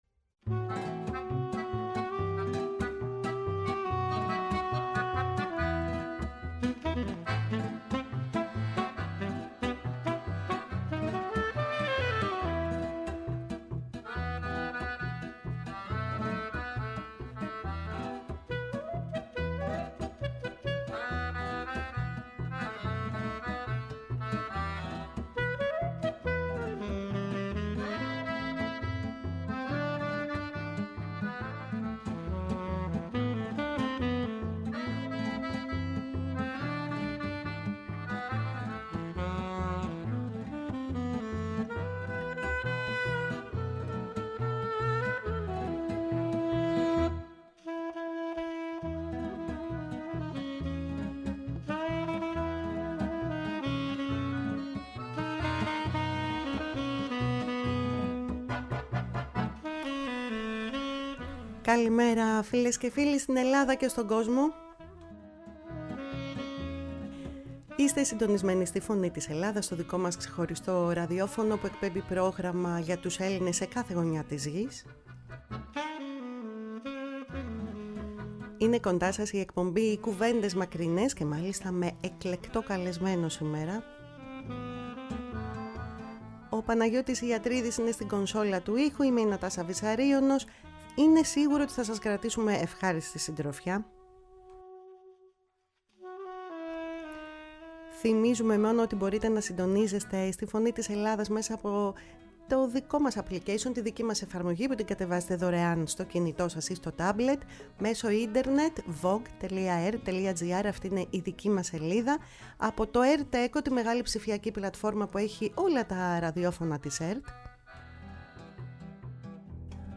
Στο στούντιο της “Φωνής της Ελλάδας”